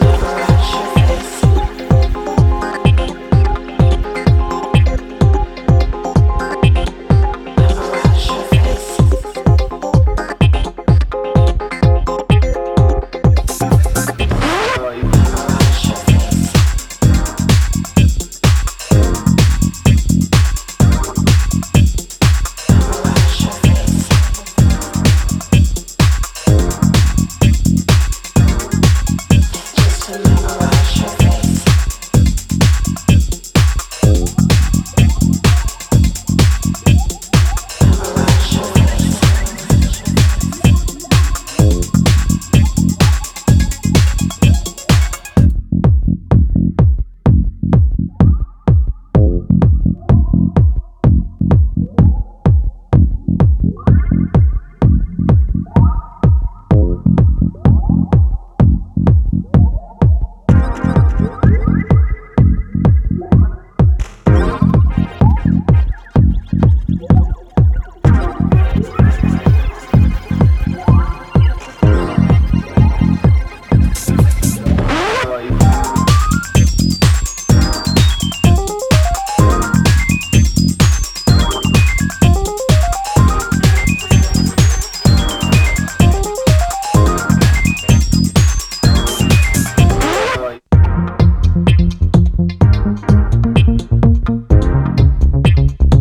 a deep, trippy journey